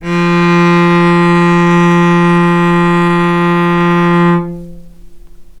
vc-F3-mf.AIF